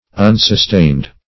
unsustained - definition of unsustained - synonyms, pronunciation, spelling from Free Dictionary